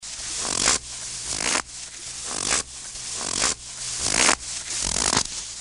MP3 · 132 KB · 立體聲 (2ch)